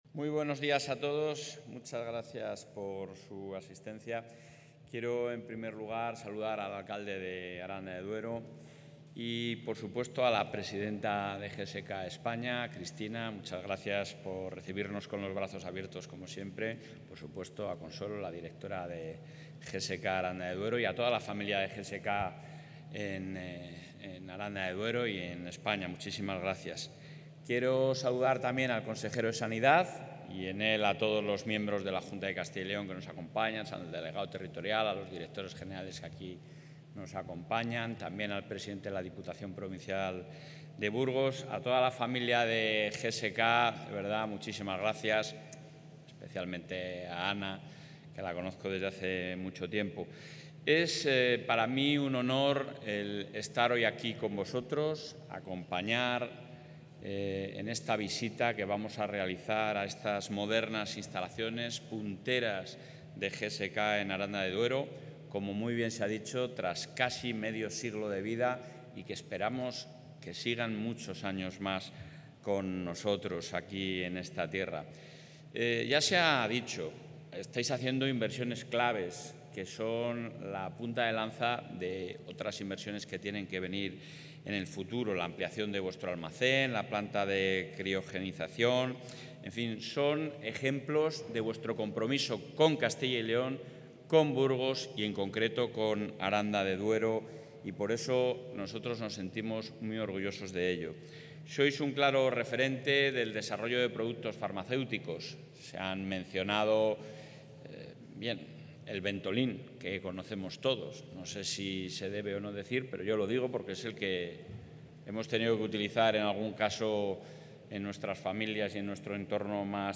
Intervención del presidente de la Junta.
El presidente de la Junta de Castilla y León, Alfonso Fernández Mañueco, ha visitado el centro de producción de la compañía GSK en la localidad burgalesa de Aranda de Duero, donde ha destacado la posición referente que ocupa la Comunidad a nivel nacional en esfuerzo tecnológico, situándose entre las cinco primeras de España en este ámbito.